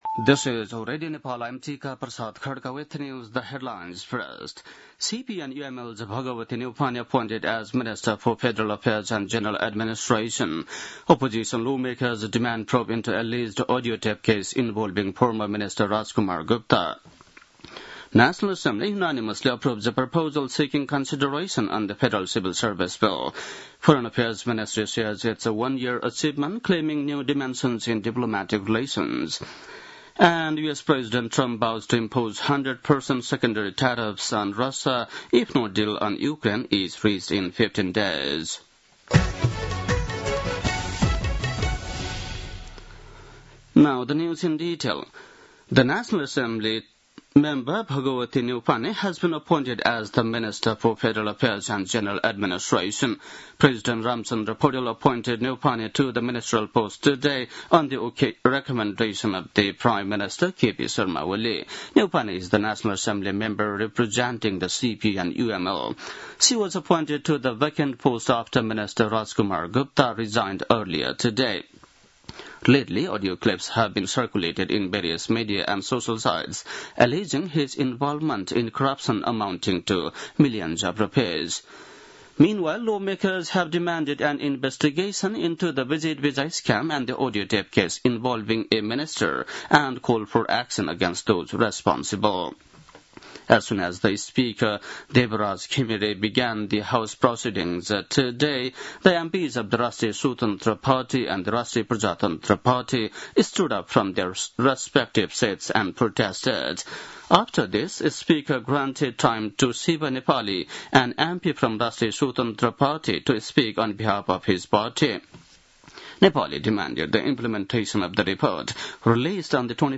बेलुकी ८ बजेको अङ्ग्रेजी समाचार : ३१ असार , २०८२
8-pm-english-news-3-31.mp3